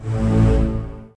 strTTE65008string-A.wav